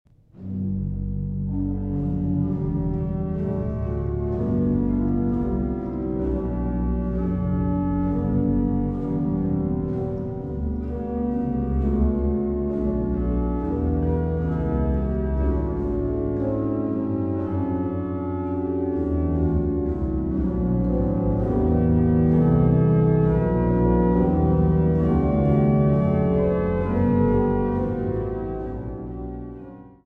Buchholz-Orgel Nikolaikirche Stralsund